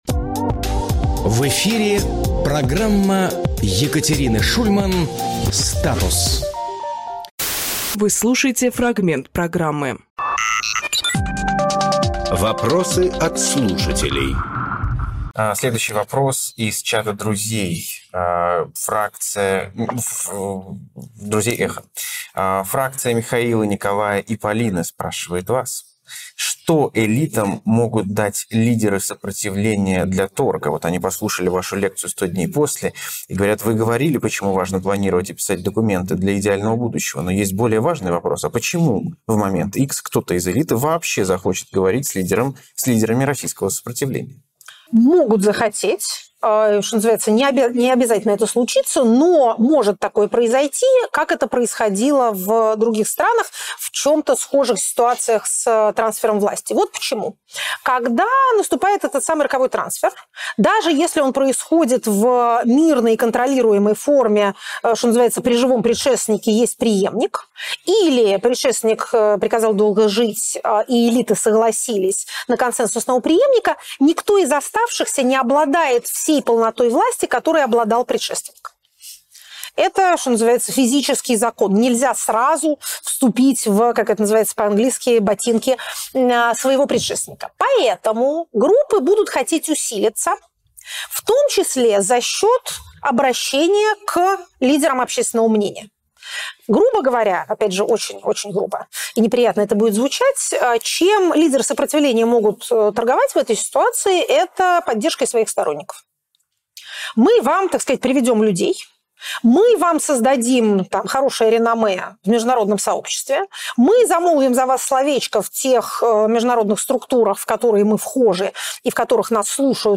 Екатерина Шульманполитолог
Фрагмент эфира от 14.05